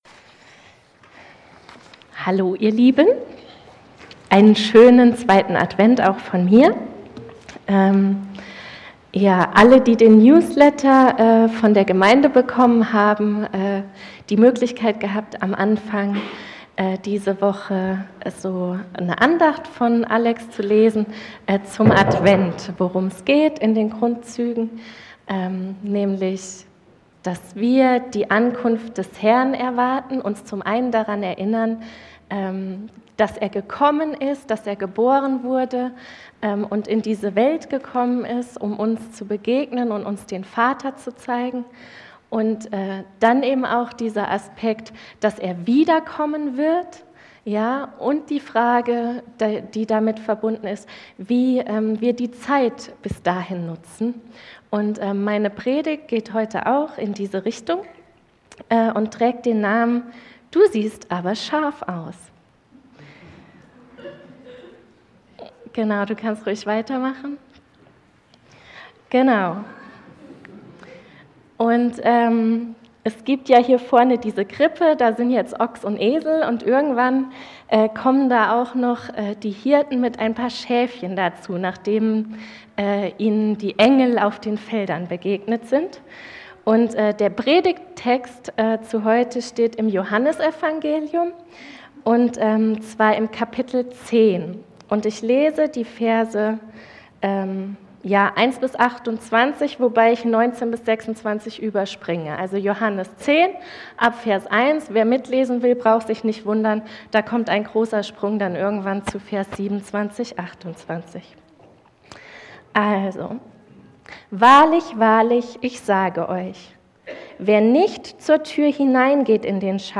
Du siehst aber Schaf aus | Marburger Predigten